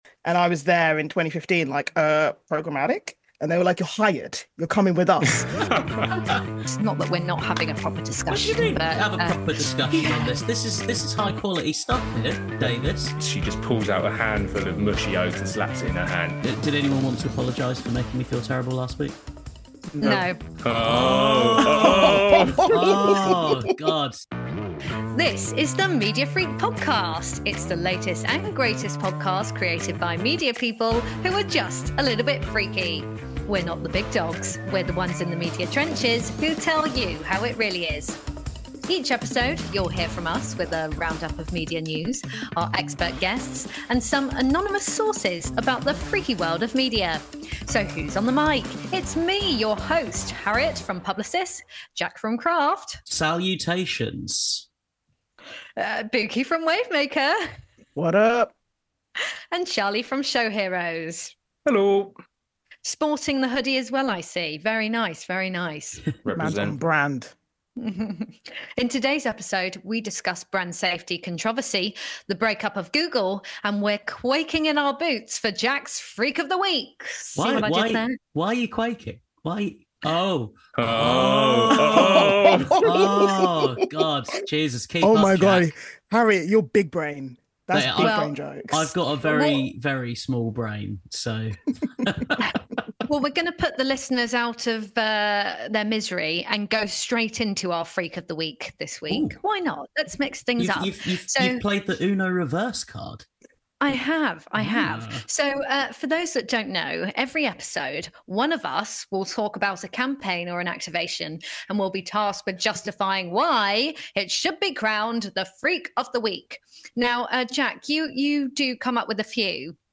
This is the marketing and advertising podcast presented by a team from across the industry.